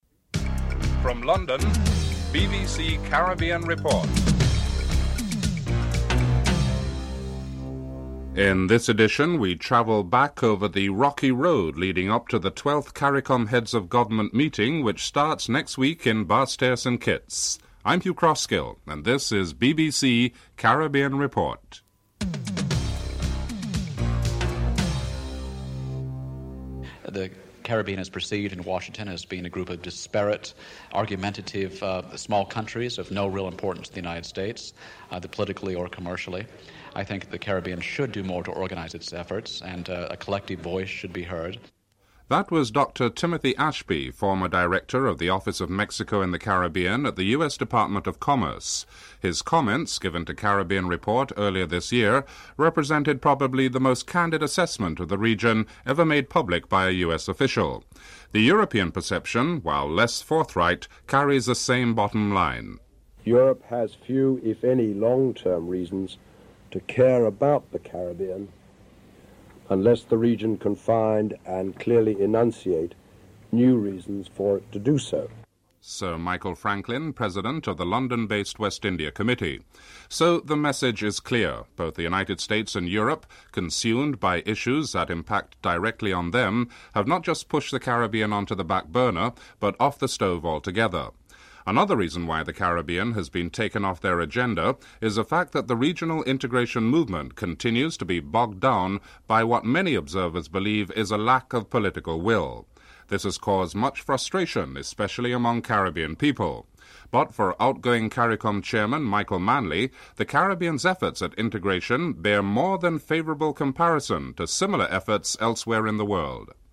1. Headline (00:00-00:26)